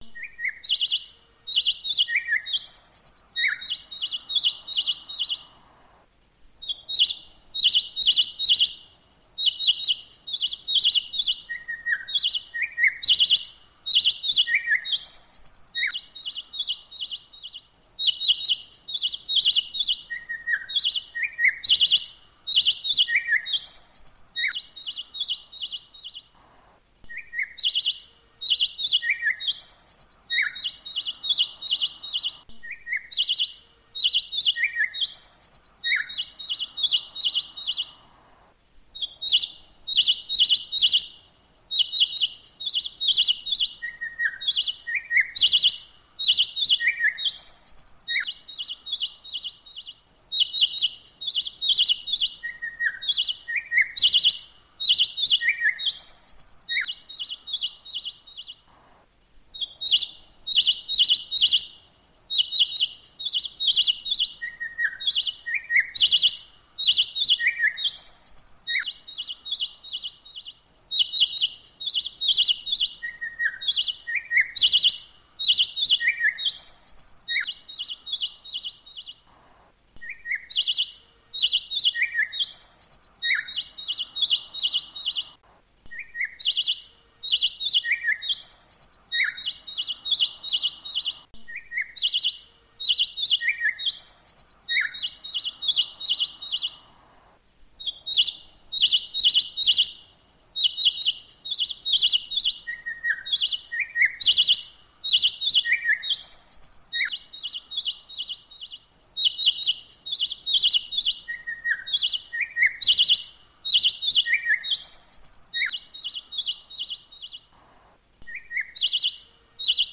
Tiếng Chim Hót líu lo (Âm thanh nền kể chuyện)
Thể loại: Tiếng chim
Description: Âm thanh nền nhẹ nhàng, trong trẻo như bản hòa ca của thiên nhiên, mang lại cảm giác yên bình, thư thái và gần gũi với rừng xanh. Âm thanh, nhạc nền kể chuyện này là tiếng chim ríu rít, tiếng chim hót véo von, tiếng chim ca hót trong sớm mai...
tieng-chim-hot-liu-lo-am-thanh-nen-ke-chuyen-www_tiengdong_com.mp3